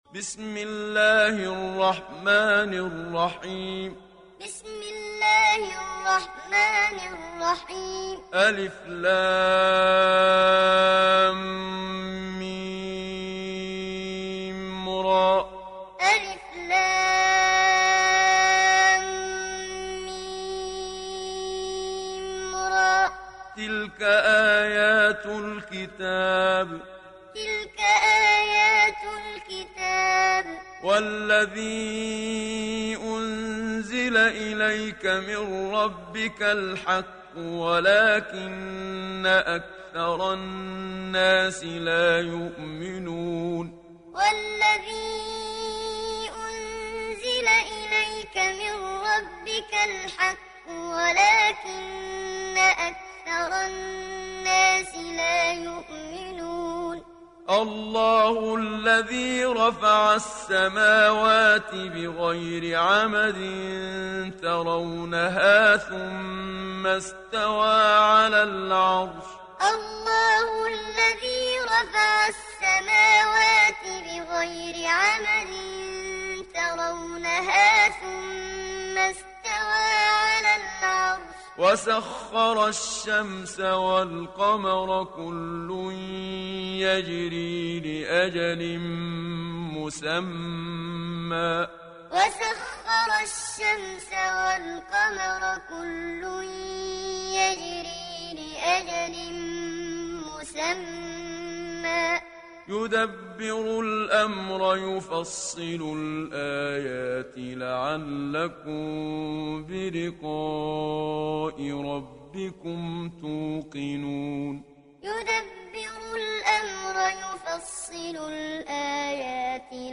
تحميل سورة الرعد mp3 بصوت محمد صديق المنشاوي معلم برواية حفص عن عاصم, تحميل استماع القرآن الكريم على الجوال mp3 كاملا بروابط مباشرة وسريعة
تحميل سورة الرعد محمد صديق المنشاوي معلم